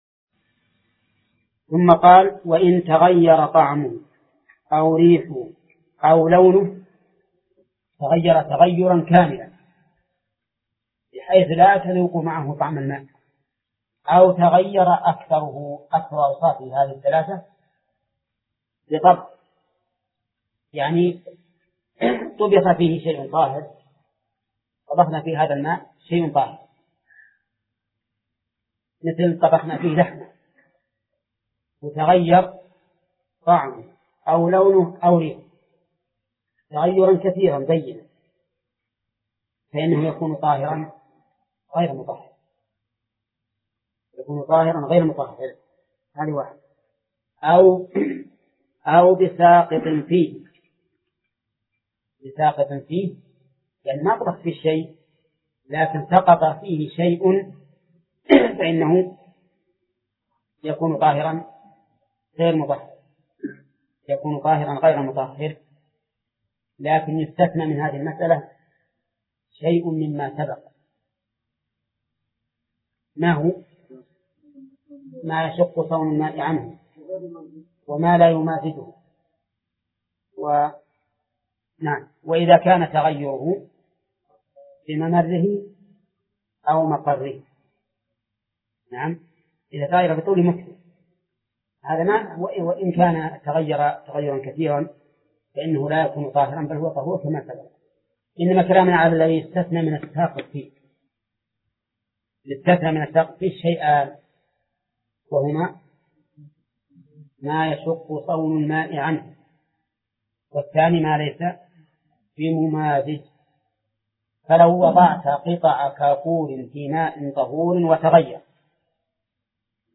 درس (3) تتمة مدخل